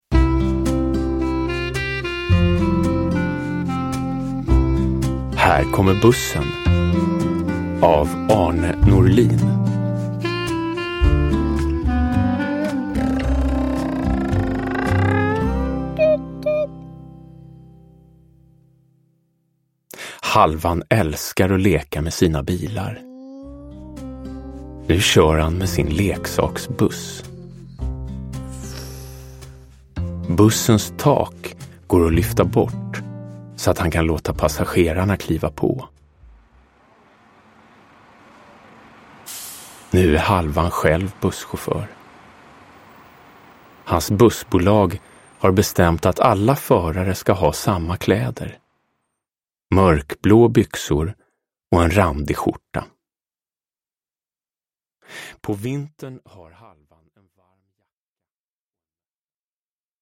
Här kommer bussen – Ljudbok – Laddas ner
Uppläsare: Jonas Karlsson